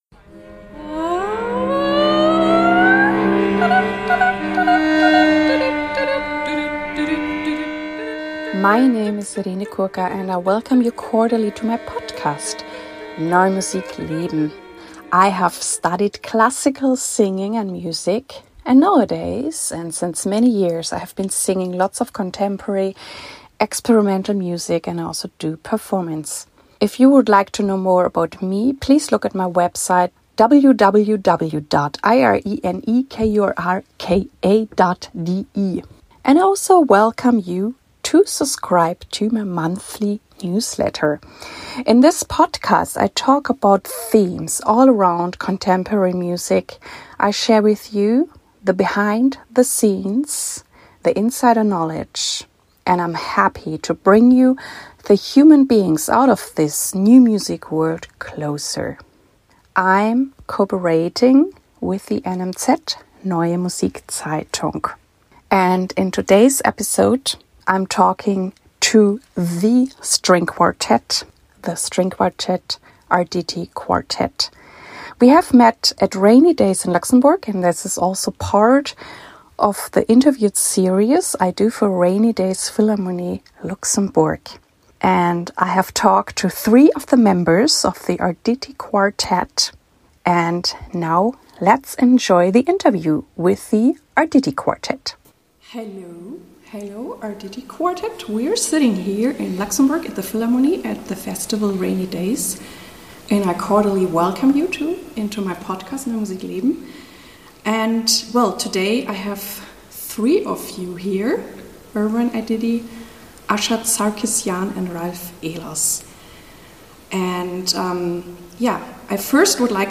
1 243 - Interview with the Arditti String Quartett - rainy days Luxembourg (3) 34:01